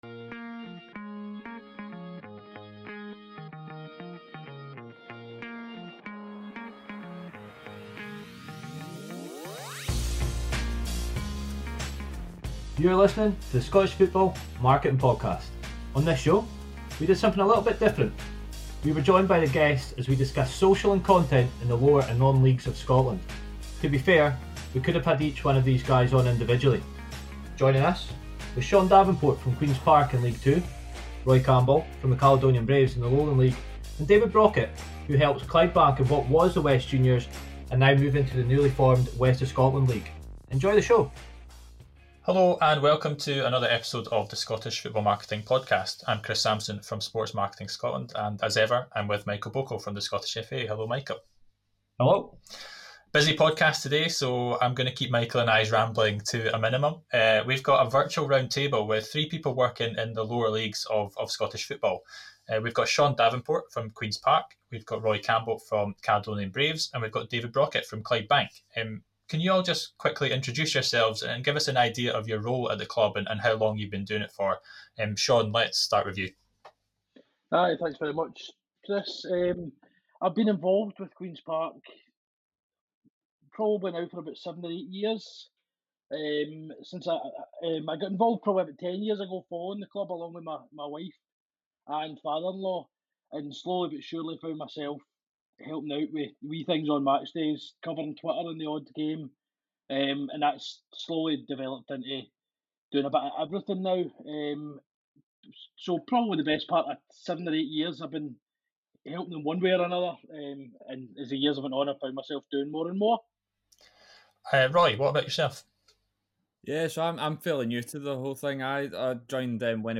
Lower/Non-League Social & Content Roundtable